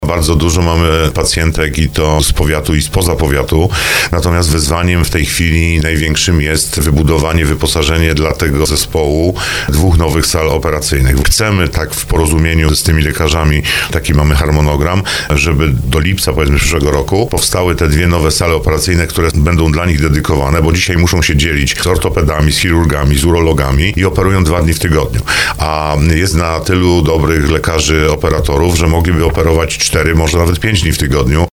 To była trafiona decyzja – tak o przekształceniu brzeskiej porodówki w oddział ginekologii onkologicznej mówi starosta powiatu brzeskiego Andrzej Potępa.
Jak podkreślił na antenie RDN Małopolska, w regionie od lat brakowało miejsca specjalizującego się w leczeniu nowotworów narządów rodnych kobiet, a zapotrzebowanie na takie świadczenia było ogromne.